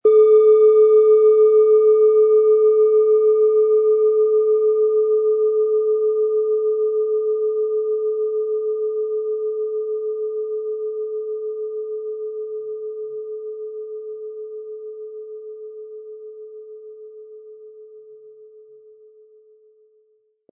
Planetenton 1
Planetenschale® Visionen und übersinnliche Begabungen & Entdecke Deine Selbsttäuschungen mit Neptun, Ø 11,5 cm inkl. Klöppel
Sie möchten den schönen Klang dieser Schale hören? Spielen Sie bitte den Originalklang im Sound-Player - Jetzt reinhören ab.
SchalenformBihar
MaterialBronze